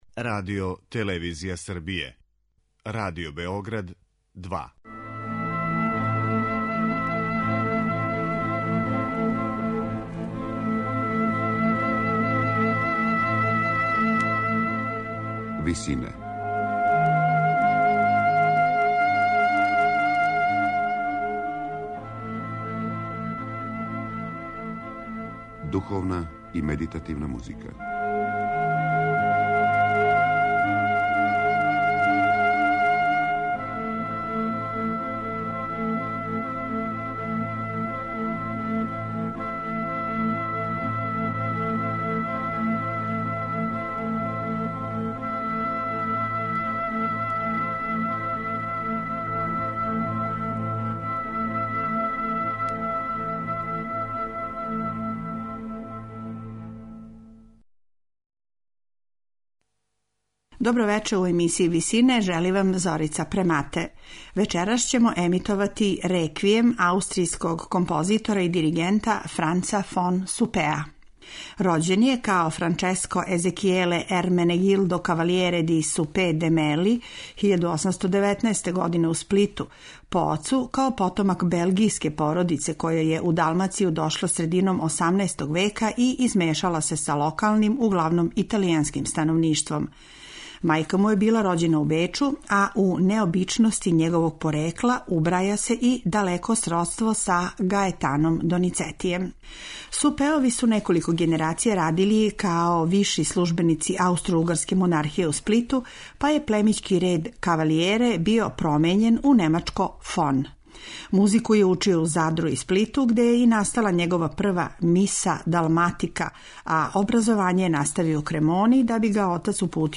са концертног снимка
сопран
мецосопран
тенор